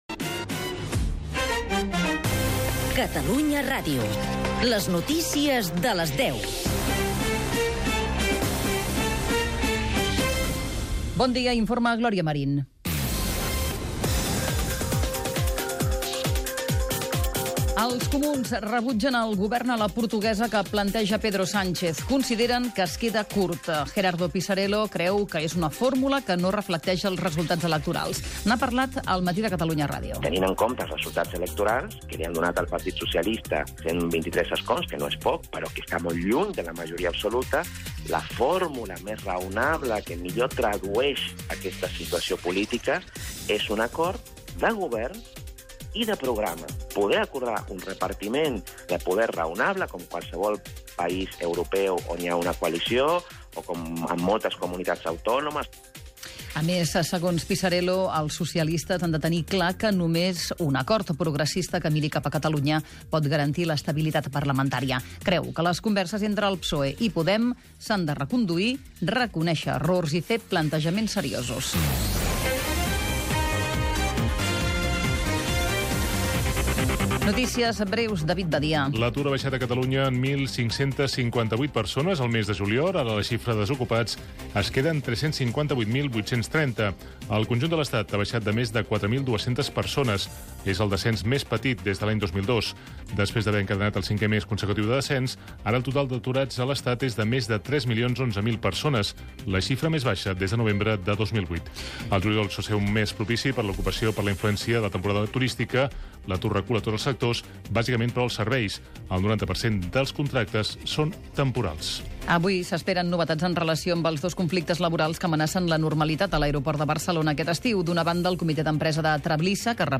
Indicatiu del programa, negociacions per fer un govern a Espanya entre Comuns i PSOE (declaracions de Gerardo Pisarello), dades de l'atur, vagues als aeroports, retallades als pressupostos de sanitat (declaracions d'Alba Vergés), etc. Esports. Careta de sortida, publicitat i promoció de la programació d'estiu
Informatiu
FM